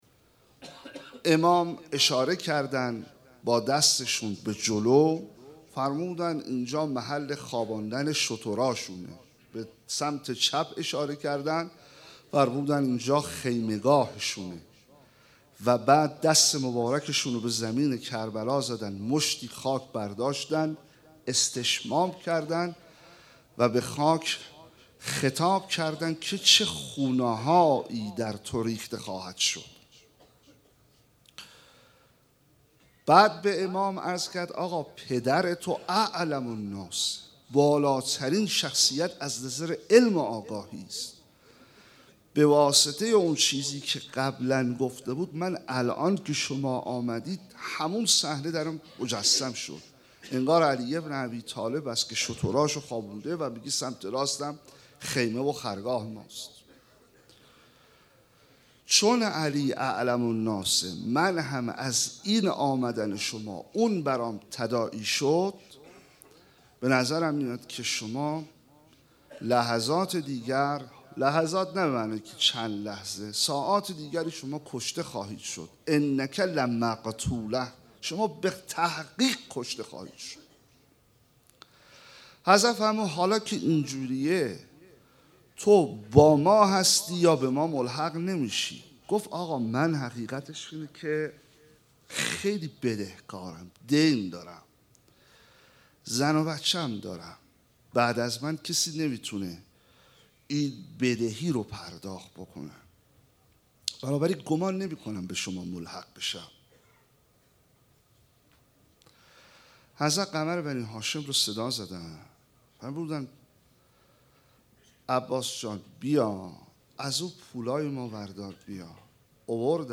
شب هشتم محرم 1436 - هیات رایه العباس B > سخنرانی